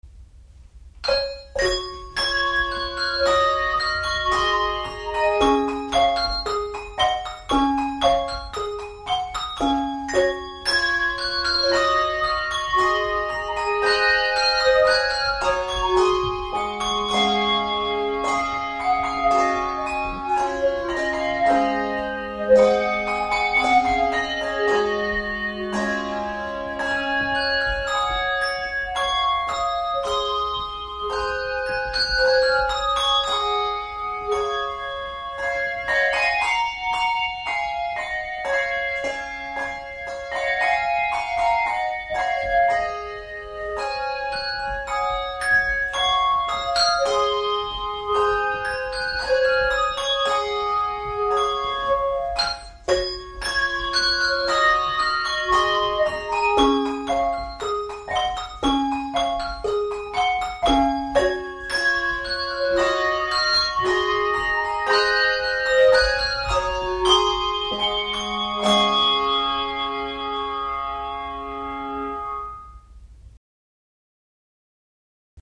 handbell quartet
Music for Handbell Quartet
No. Octaves 3 Octaves